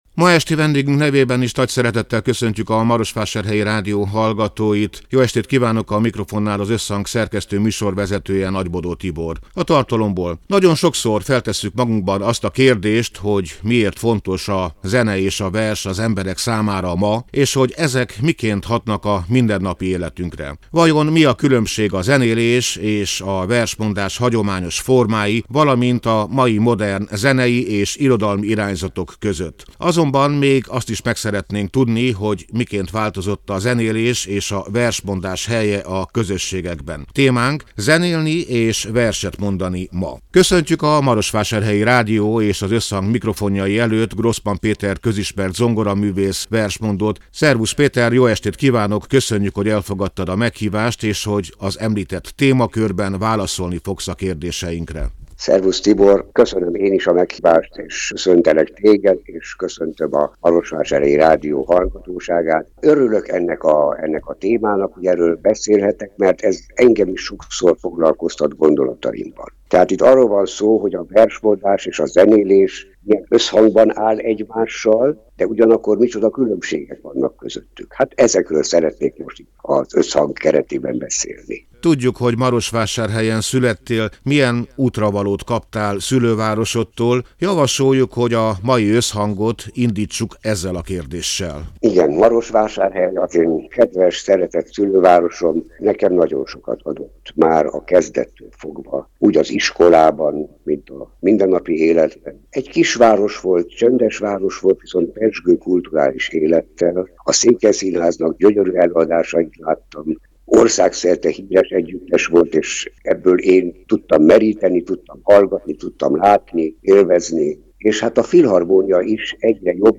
A Marosvásárhelyi Rádió Összhang (elhangzott: 2025. április 9-én, szerdán délután hat órától) c. műsorának hanganyaga: Nagyon sokszor, feltesszük magunkban azt a kérdést, hogy miért fontos a zene és a vers az emberek számára ma, és hogy ezek miként hatnak a mindennapi életünkre?